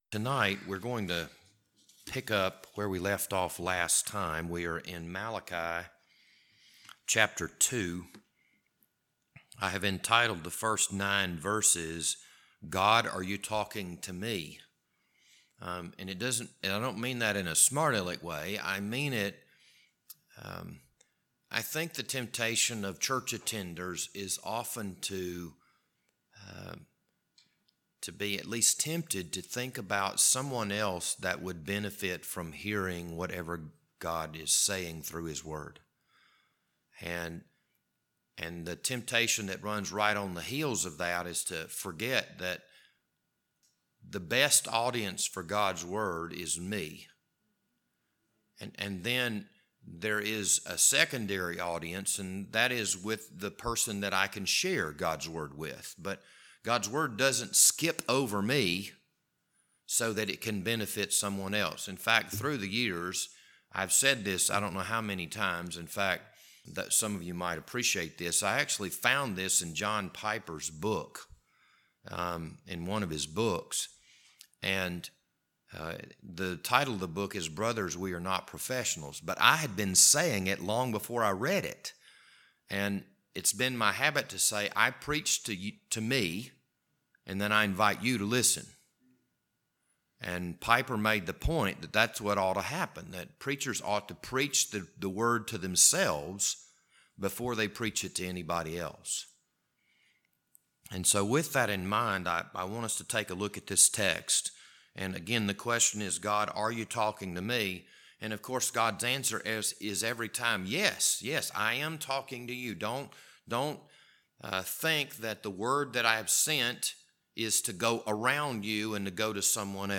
This Wednesday evening Bible study was recorded on September 1st, 2021.